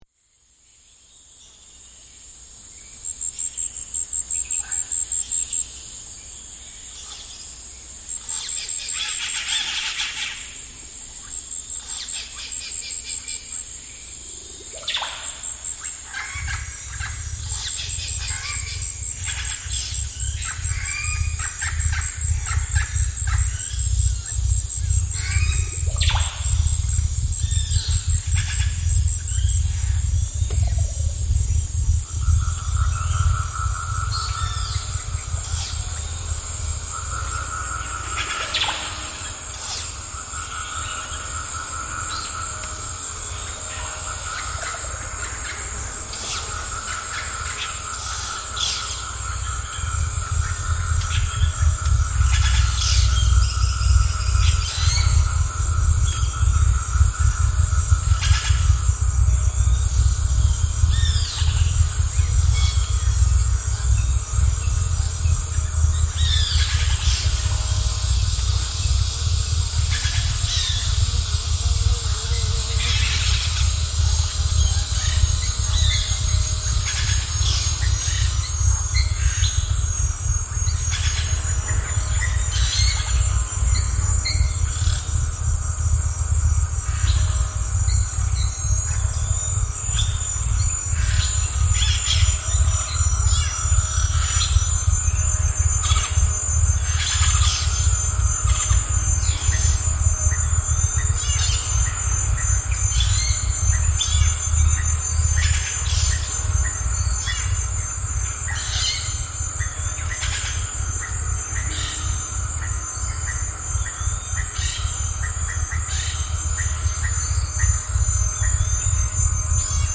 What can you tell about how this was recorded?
Yasuni biosphere reserve, Ecuador